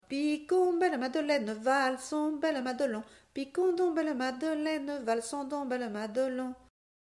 danse : polka piquée
Témoignage et chansons
Pièce musicale inédite